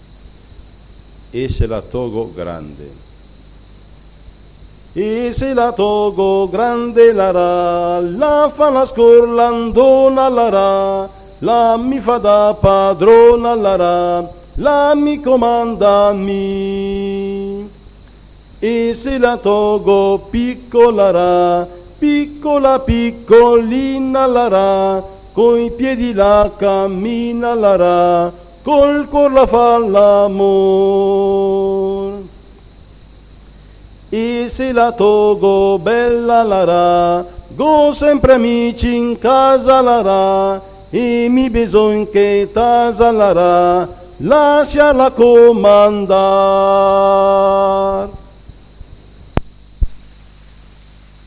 Registrazioni di canti popolari effettuate presso la sede del Coro Genzianella di Condino. 11 settembre 1972. 1 bobina di nastro magnetico.